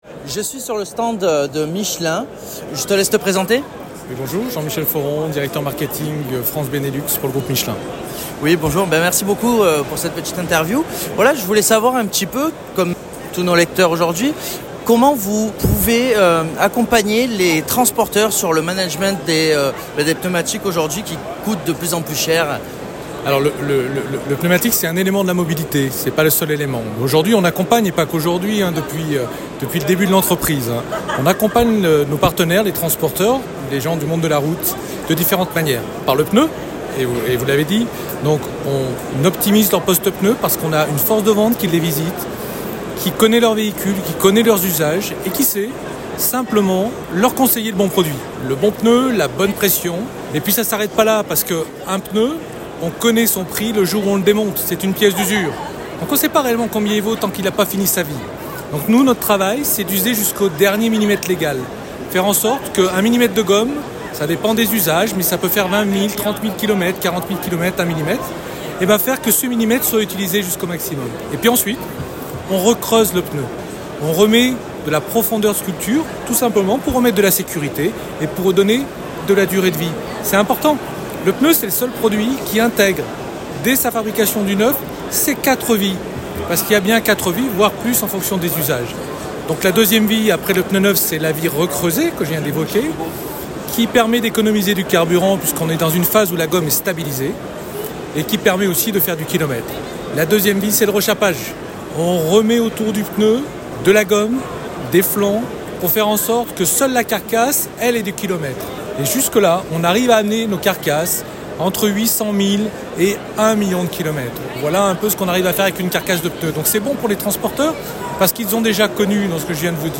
Le 23/11/2023 – EUREXPO Chassieu – SOLUTRANS
INTERVIEW